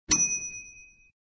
SElevatorArrive.ogg